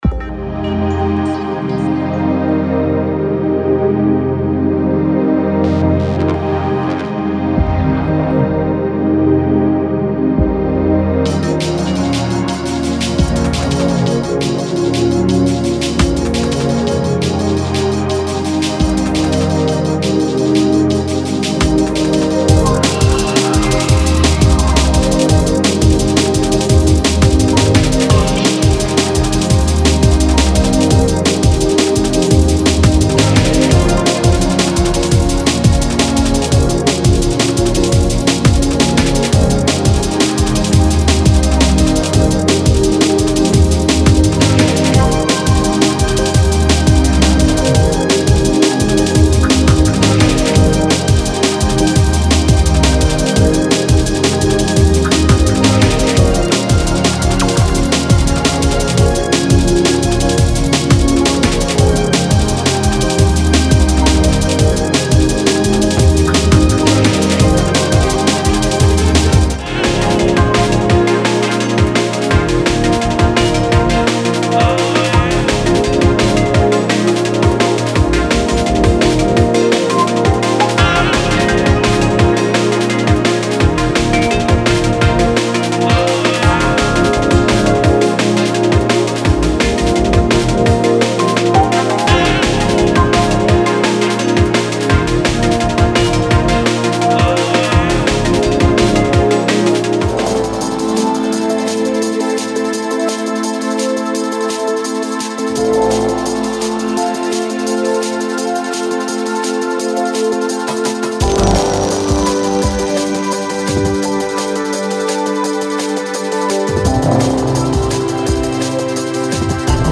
Genre:Jungle
ワンショット、ベースグルーヴ、キーボード、そして楽曲の一部を収録しており、どんなプロダクションにも刺激を与えてくれます。
この先進的なサンプルパックは、チルアウト、アンビエントDnB、そしてもちろんディープジャングルミュージックに最適です。